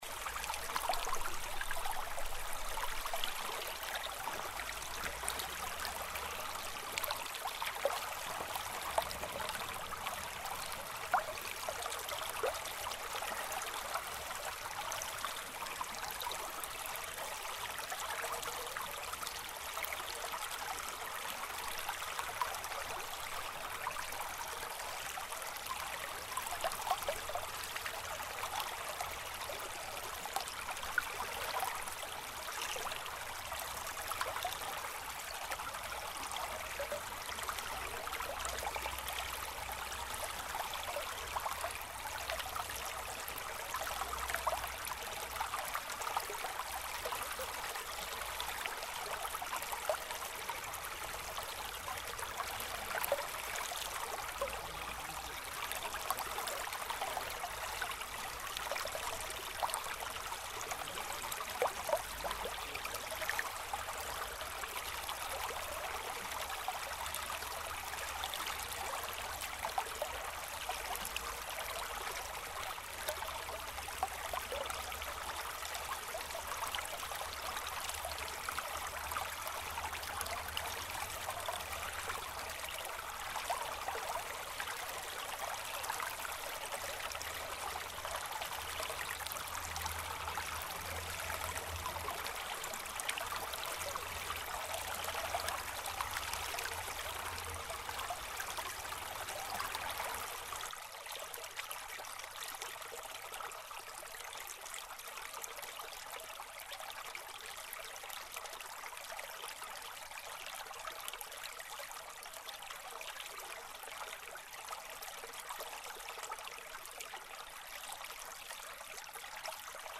Звуки ручья